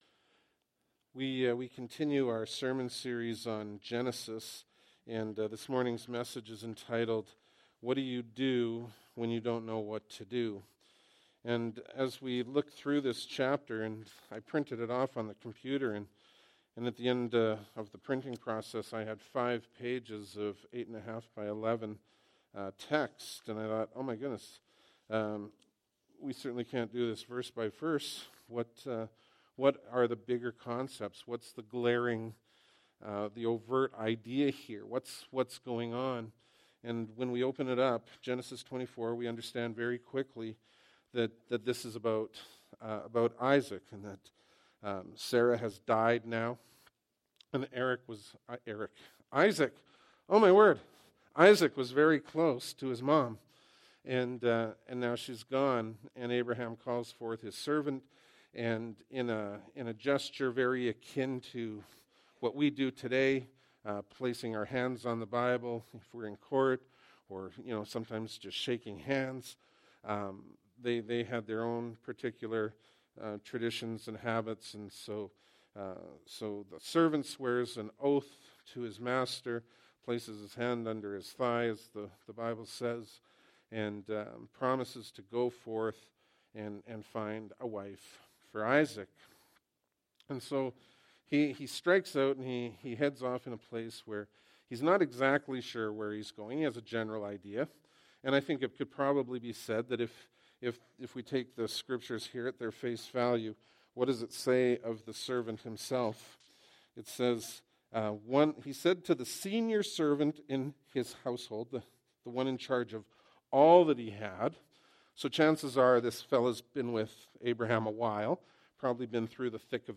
Bible Text: Genesis 24 | Preacher: